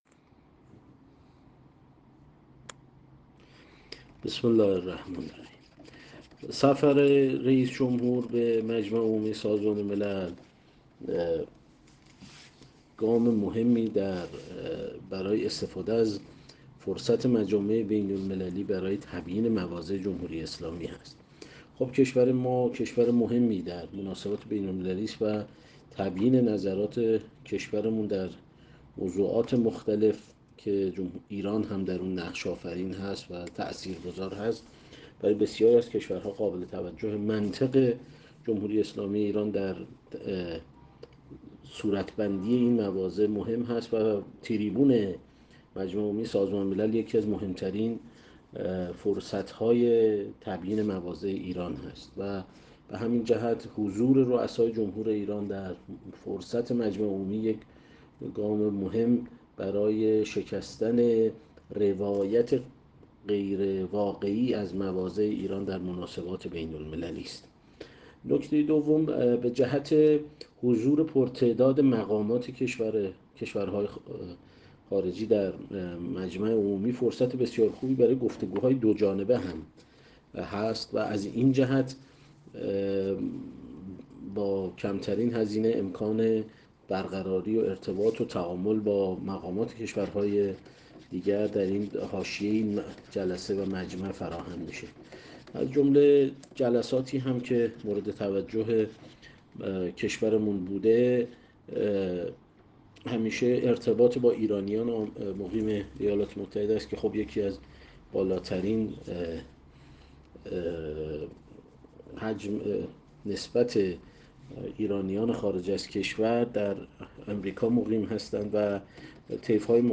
عمویی در گفت‌وگو با ایکنا مطرح کرد: